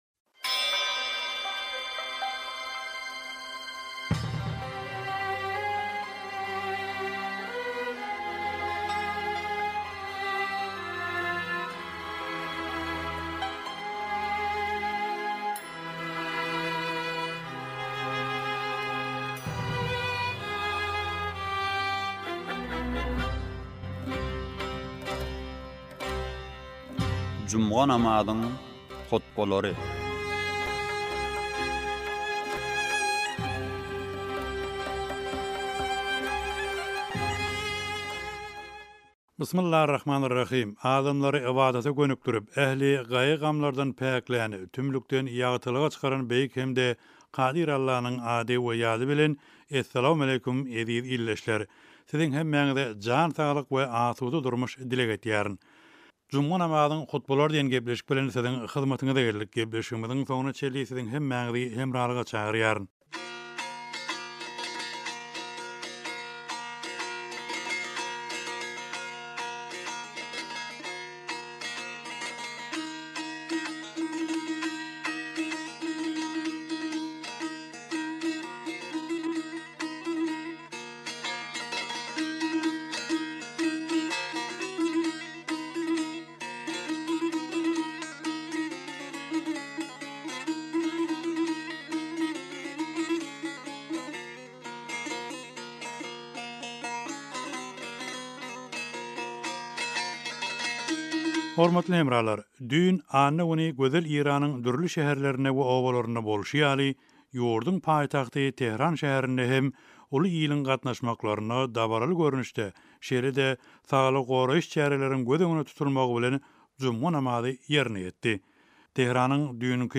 juma namazyň hutbalary
Tehraniň juma namazy